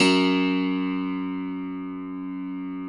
53q-pno04-F0.wav